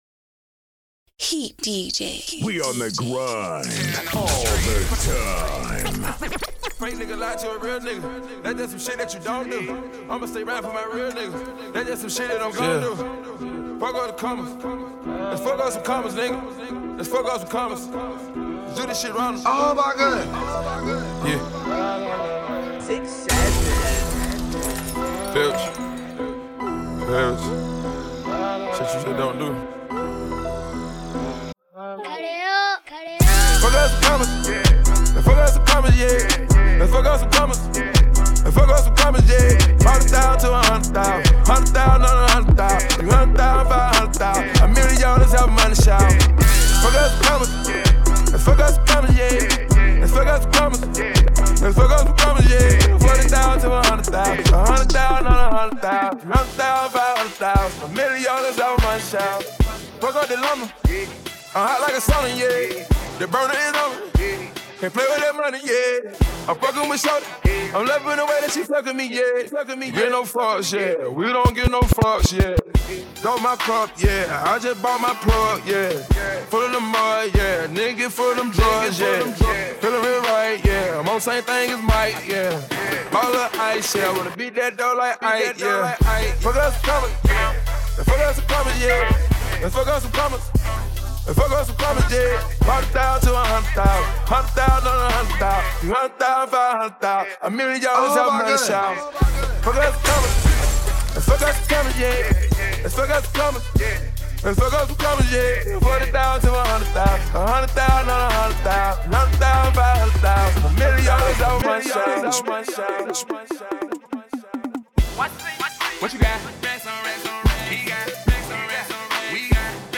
DJ Mixes
A COLLECTION OF FIRE NOSTALGIC STREET MUSIC!